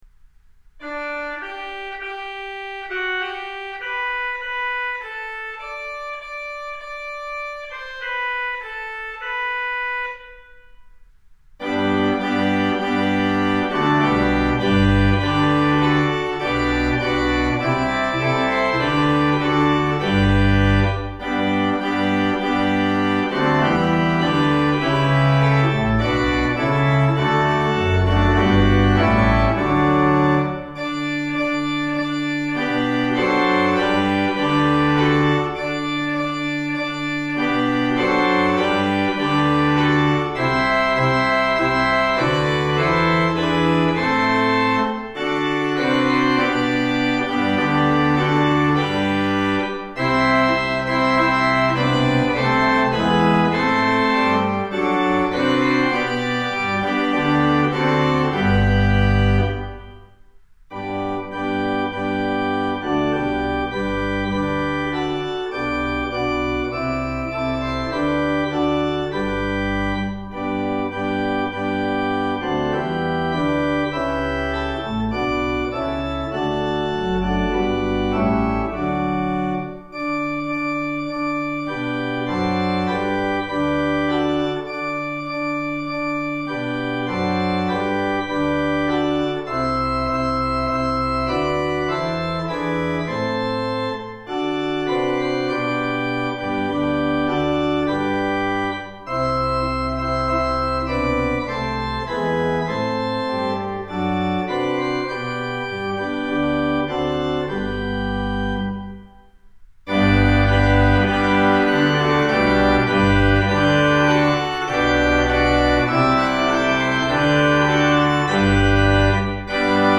Hark, the herald angels sing – Organist on demand
Organ: Moseley